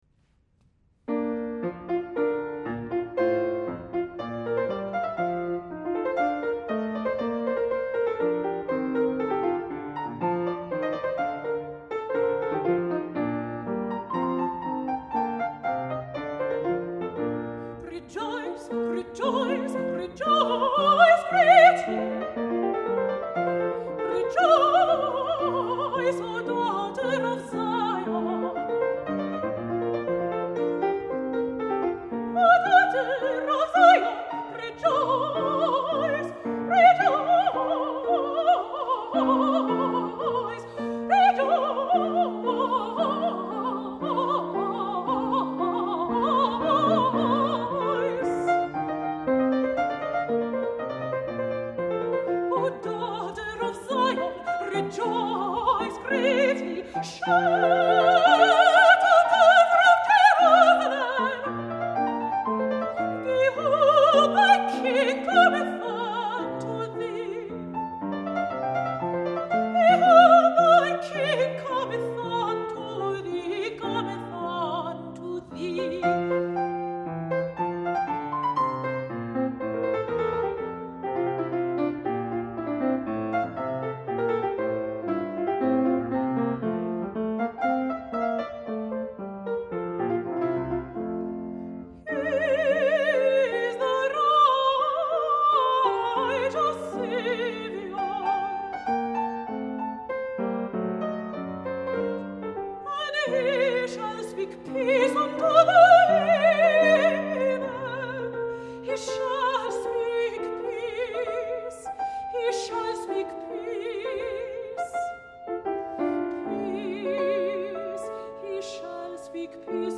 piano
St. Andrew's Presbyterian Church, Toronto